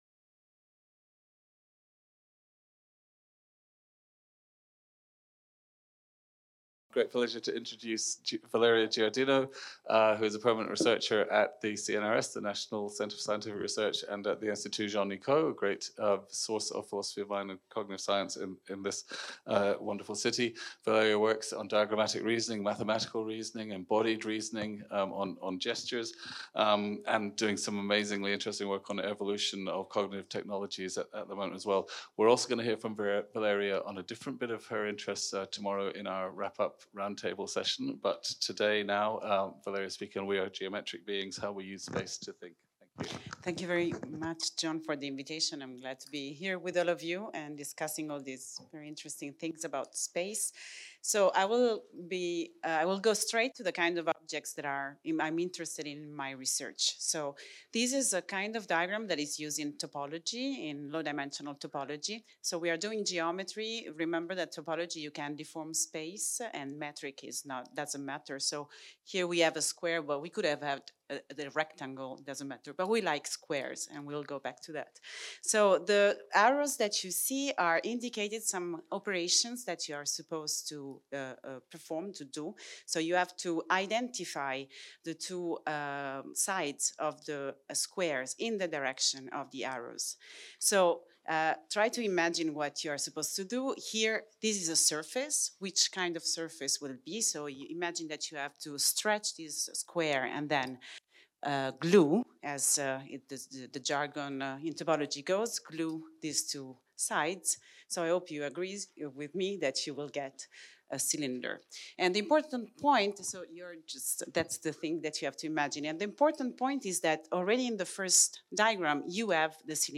Intervention